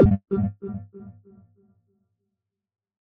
corrupt_tick.ogg